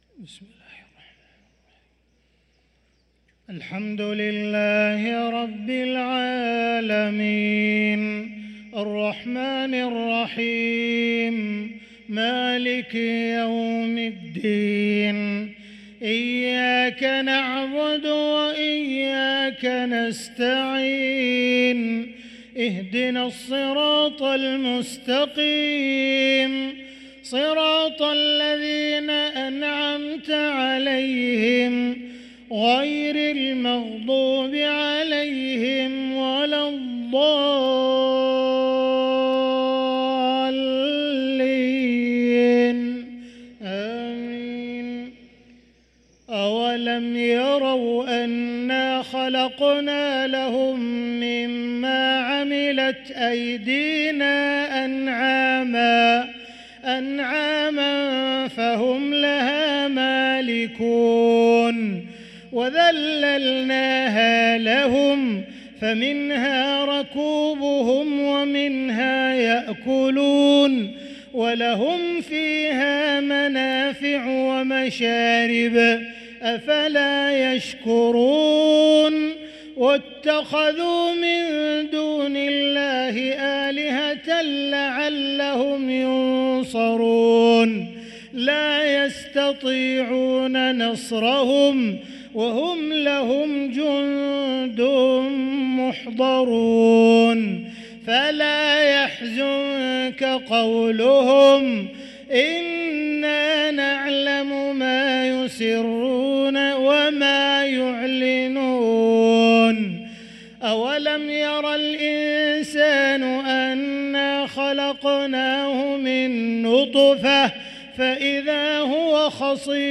صلاة العشاء للقارئ عبدالرحمن السديس 13 رجب 1445 هـ